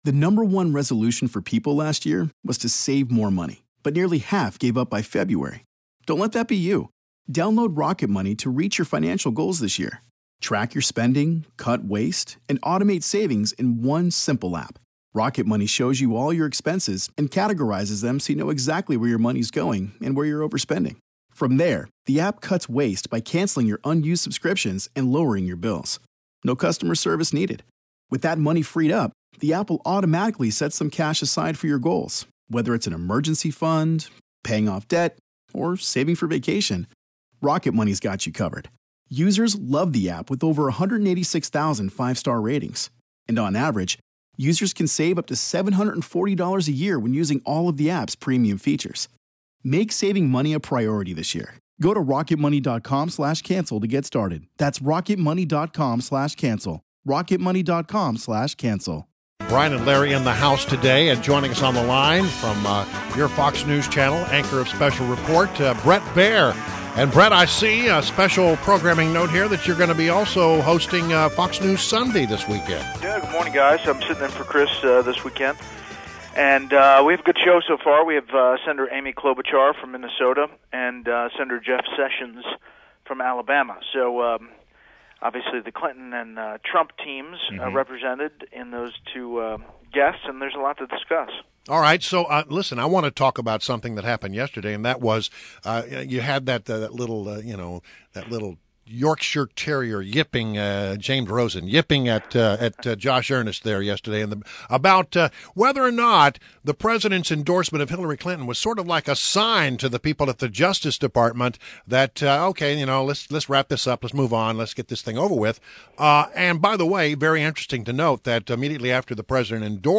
WMAL Interview - BRET BAIER - 06.10.16